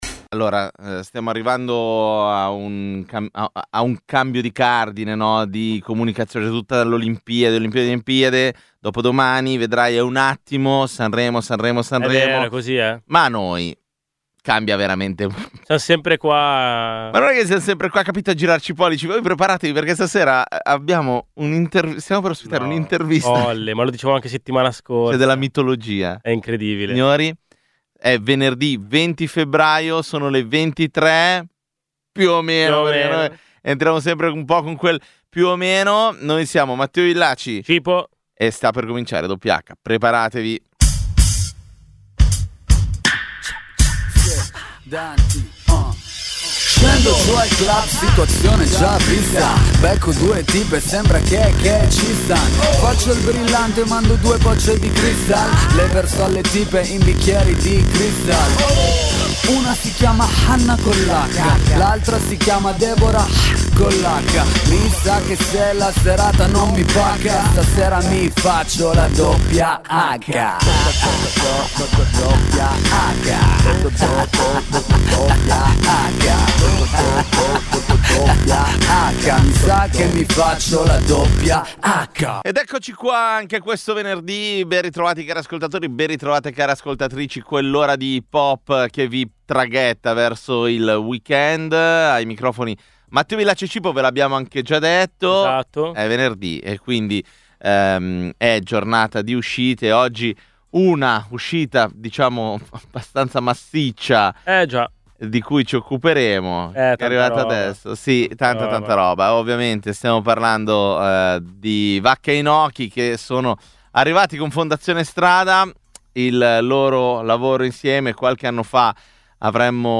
Dal 2011 è la trasmissione dedicata all’hip-hop di Radio Popolare.